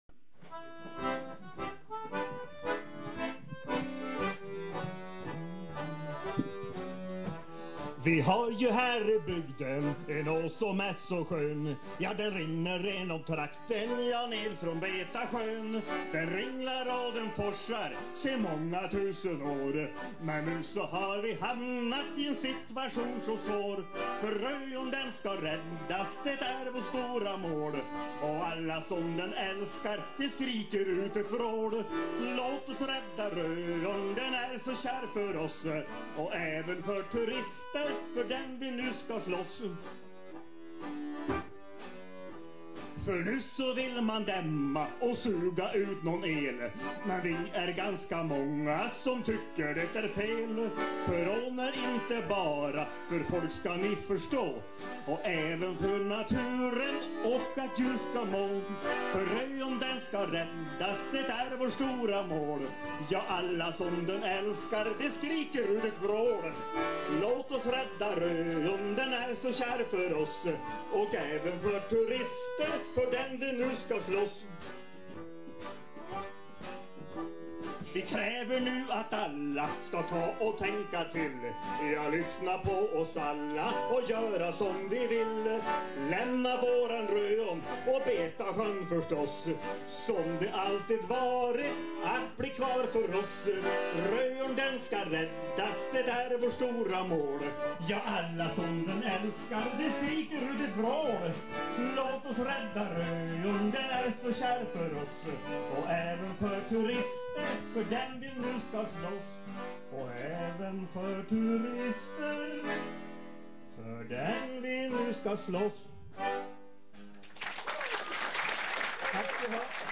(Kolla loggan på dragspelet!)